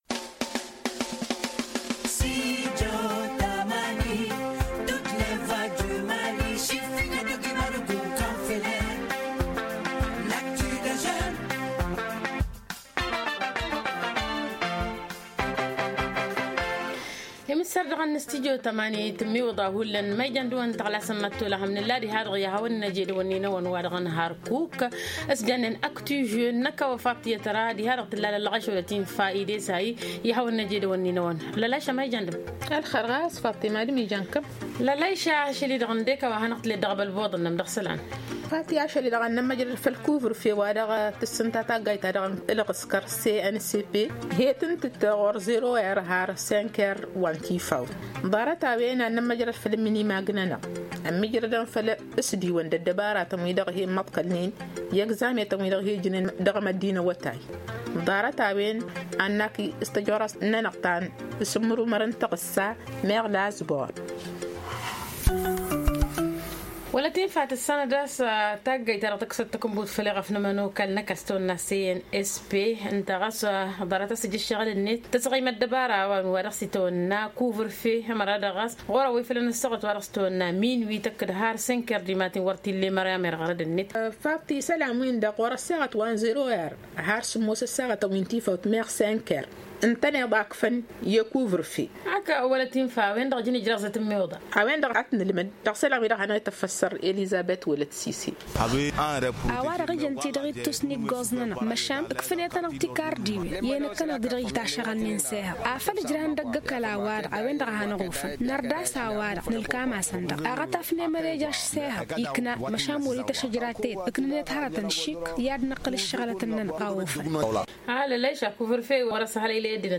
Élèves et parents s’expriment sur le sujet dans le mini mag.